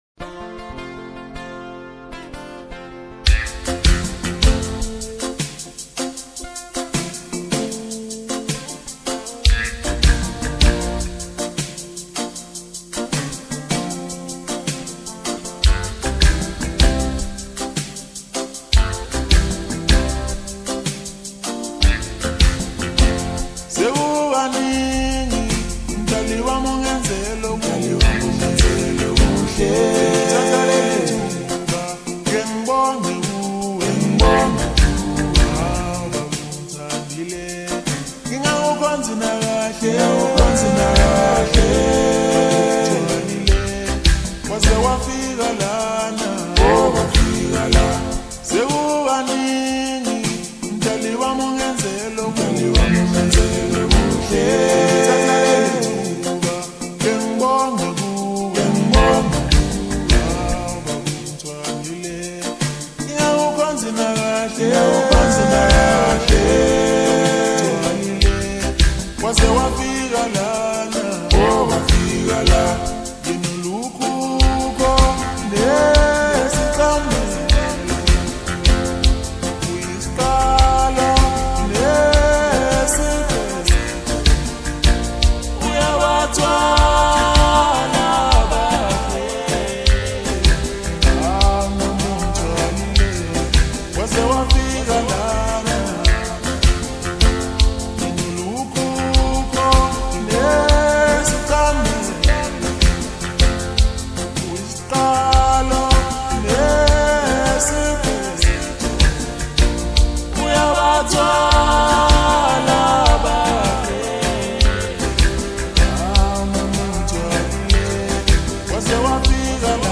Home » Maskandi » DJ Mix